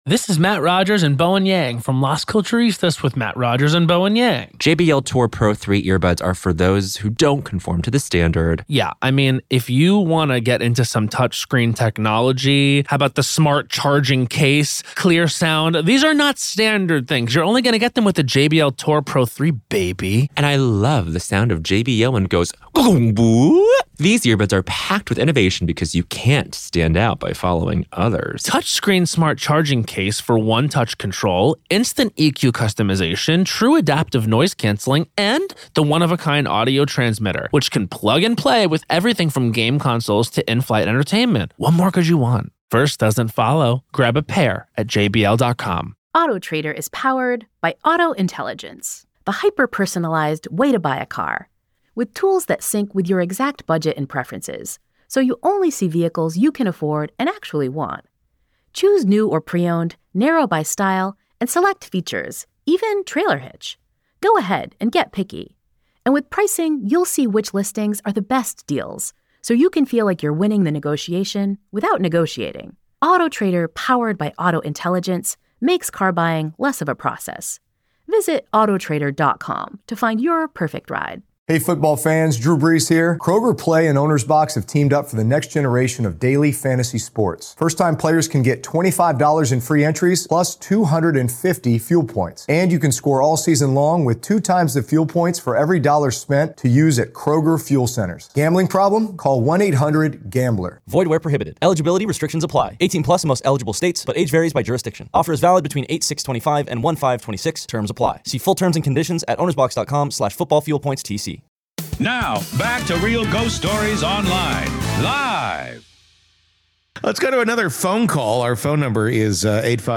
It all sounds harmless—until it wasn’t. This caller describes how she and a friend obsessed over spirit communication, always drawing the same spirit… until one day, the board went silent.